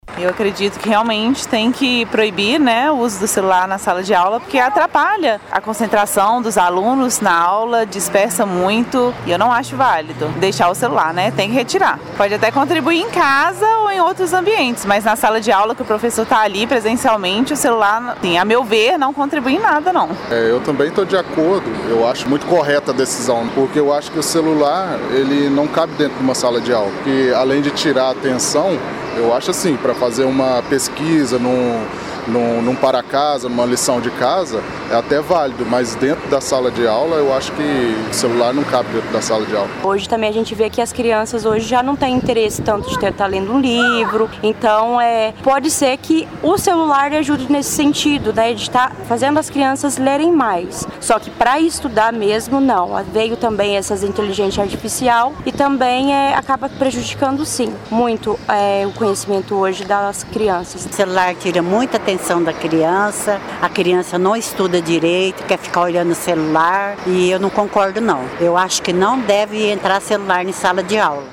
O Jornal da Manhã foi às ruas de Pará de Minas para apurar se por aqui também a população se posiciona contrária ao celular na escola.